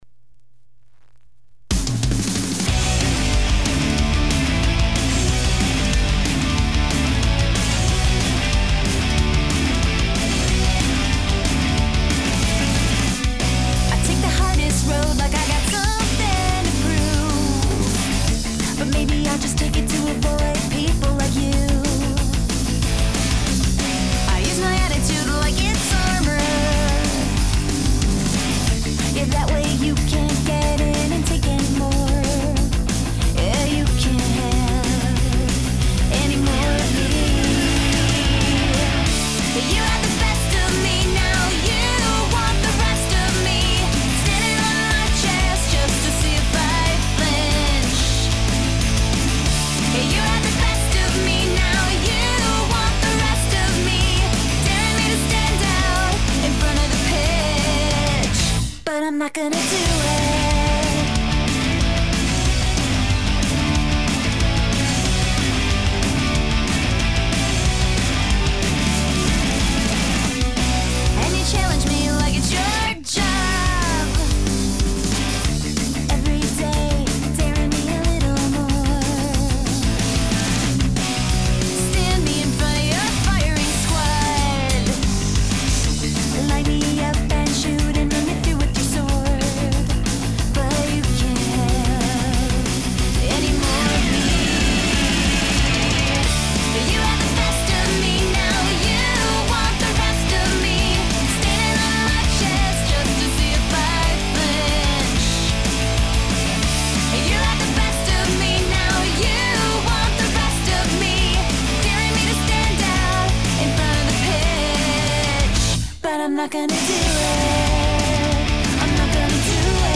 is alright, and it has a good tempo.